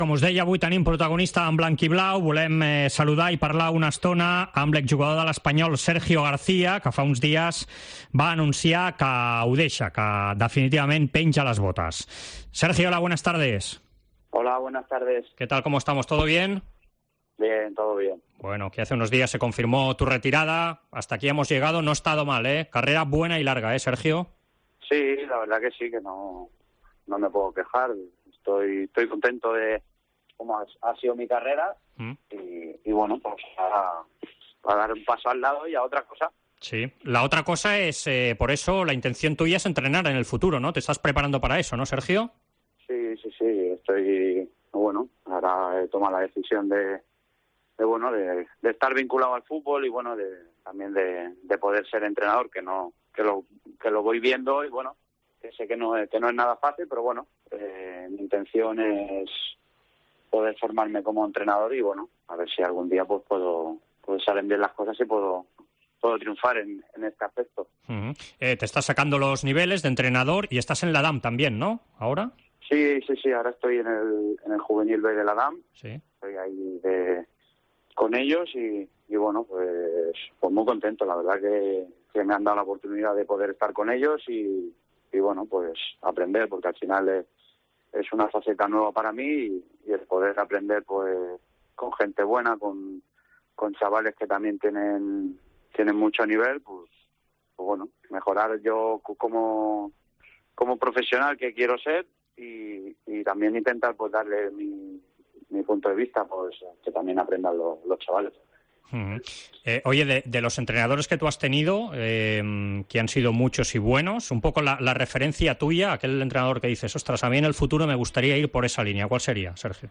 AUDIO: Entrevista al exjugador blanquiazul que ha colgado las botas esta temporada y se ha pasado a los banquillos en el Juvenil B de la Damm.